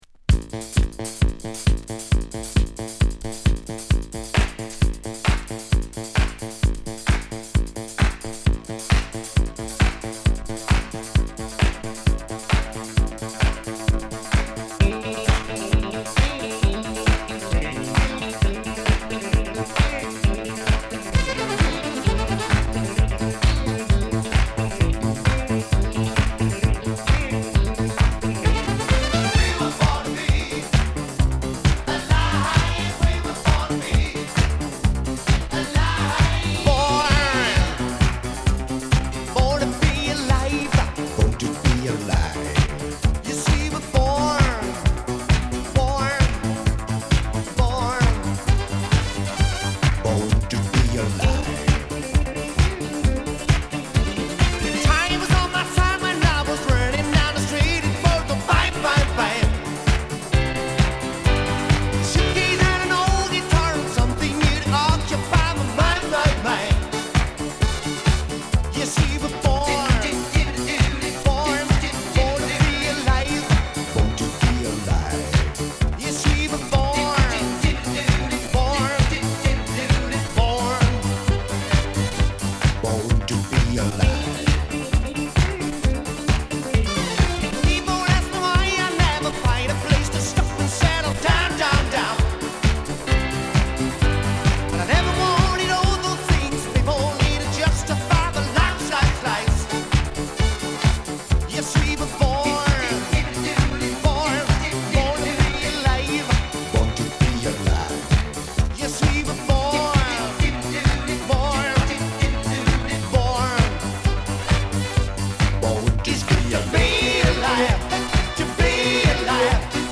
軽快なホーンやギターリフが疾走感あるビートに乗るハイエナジー12インチ！